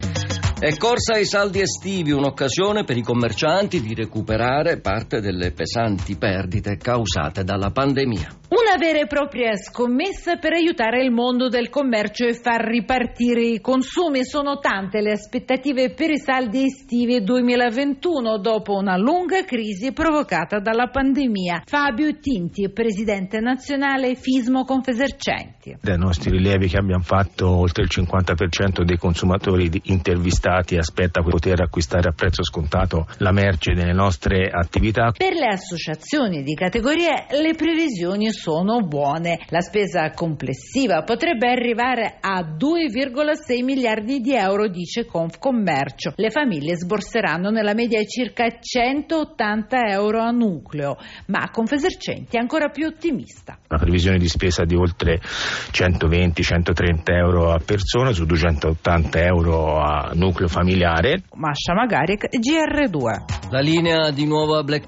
Intervistato su Radio Rai e Radio24 ha commentato i dati del sondaggio Swg per Fismo Confesercenti